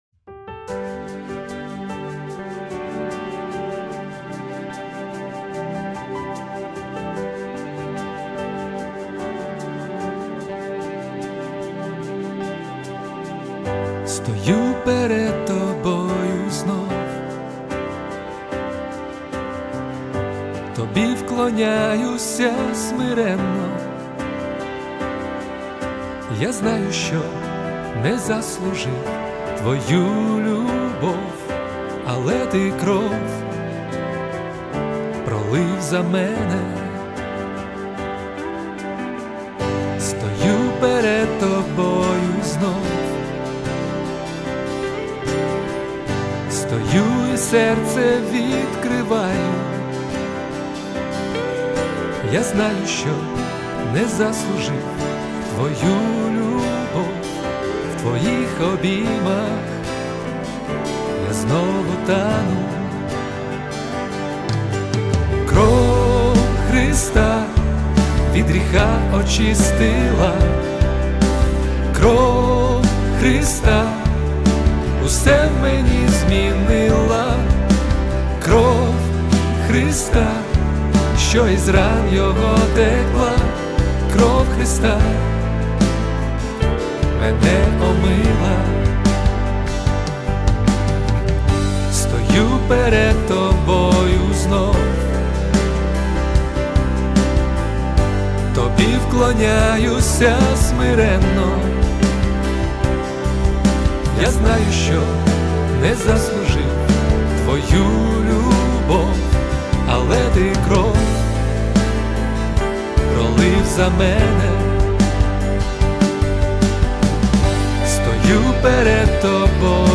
332 просмотра 556 прослушиваний 25 скачиваний BPM: 74